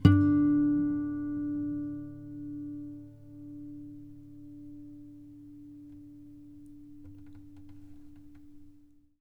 harmonic-02.wav